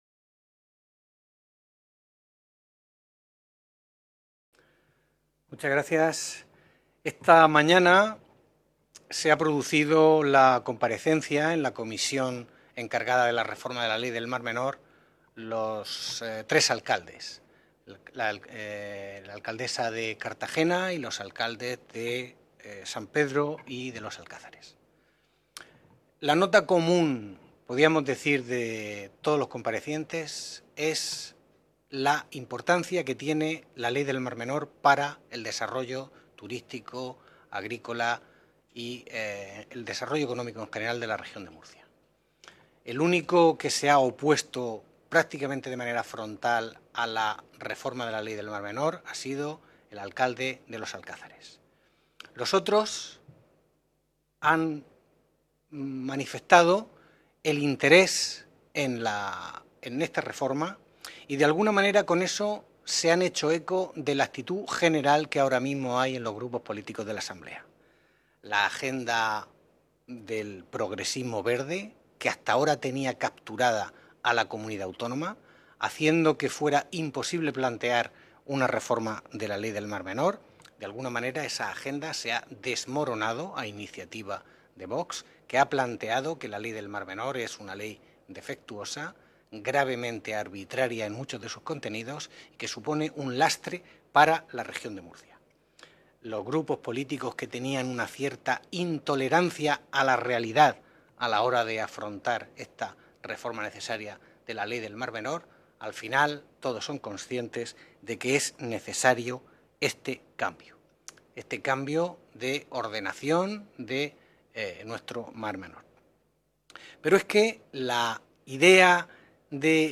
Ruedas de prensa tras la Comisión de Asuntos Generales e Institucionales, de la Unión Europea y Derechos Humanos
• Grupo Parlamentario Vox